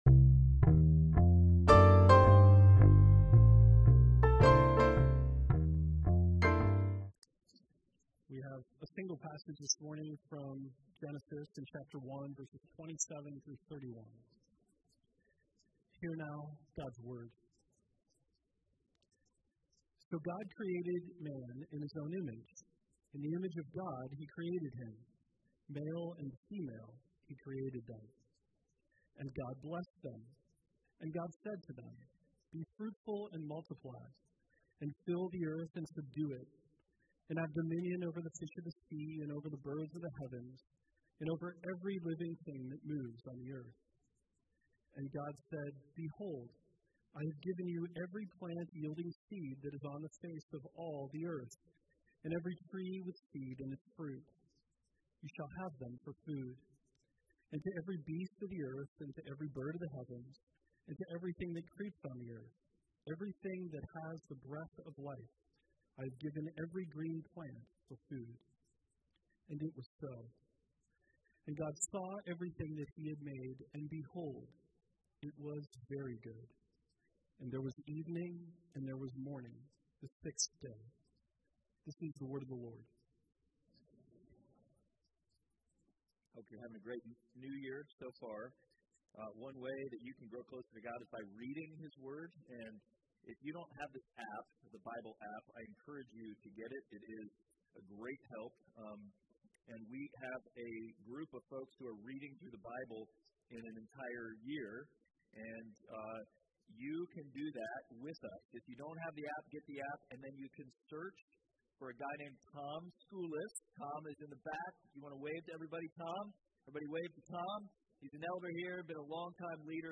Passage: Genesis 1:27-31 Service Type: Sunday Worship